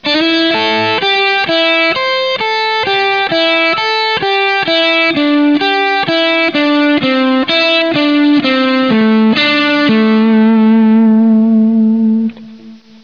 בסגנון בלוזי אופייני.
וקצת יותר לאט